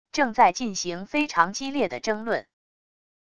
正在进行非常激烈的争论wav音频